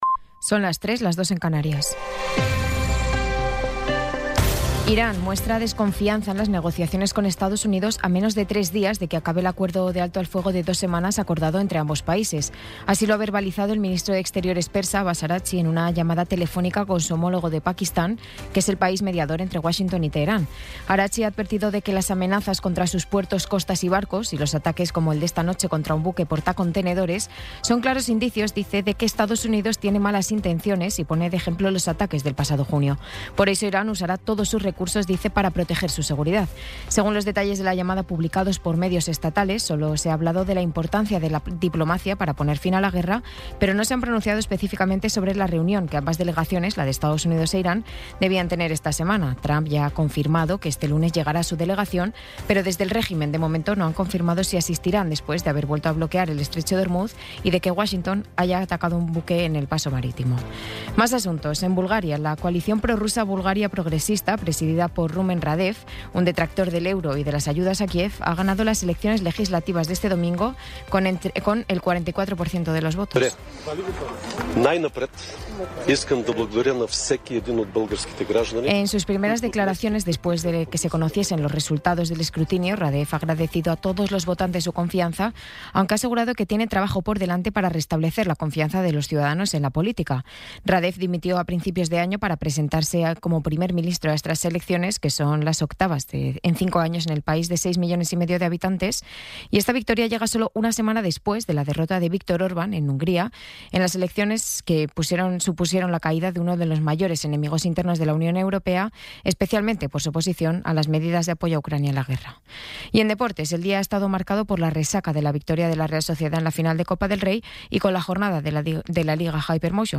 Resumen informativo con las noticias más destacadas del 20 de abril de 2026 a las tres de la mañana.